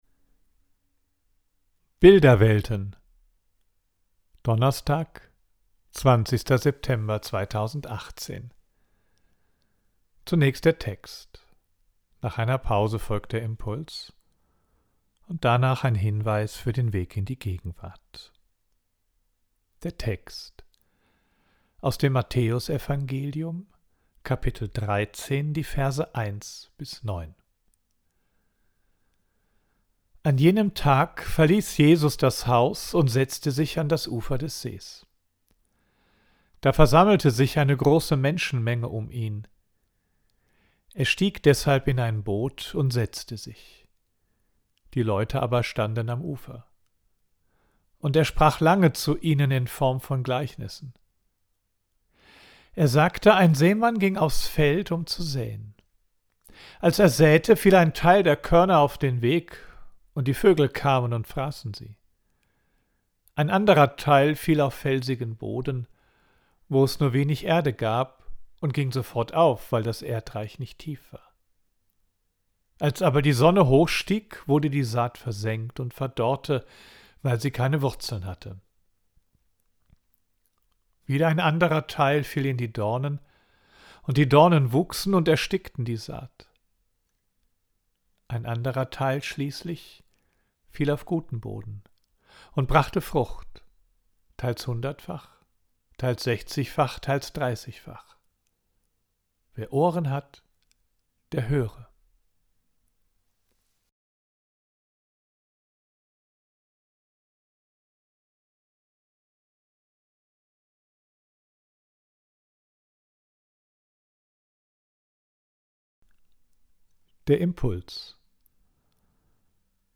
Audio: Text und Impuls